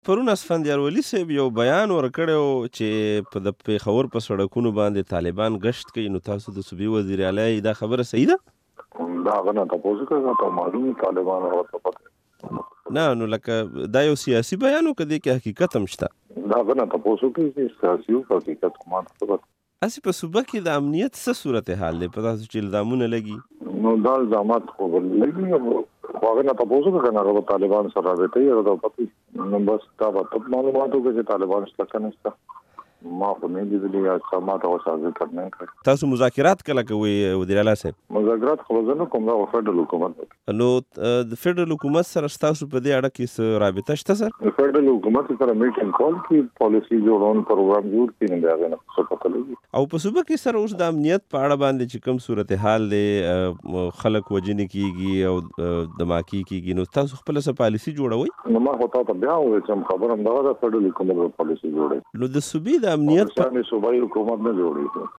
له پروېز خټک سره مرکه